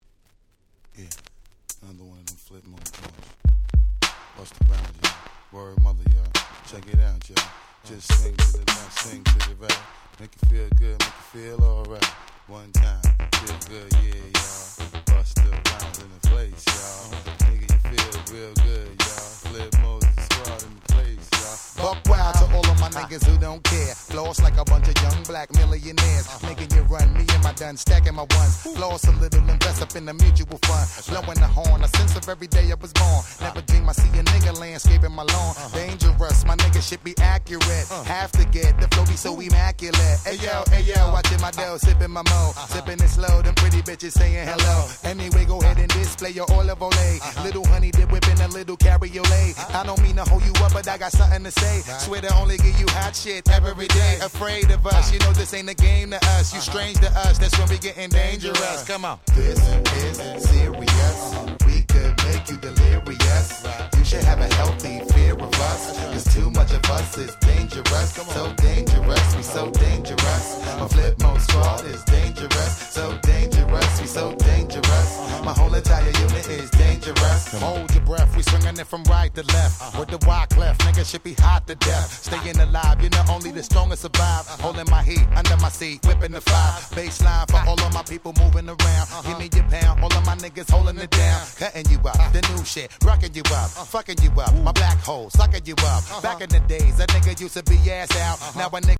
97' Super Hit Hip Hop !!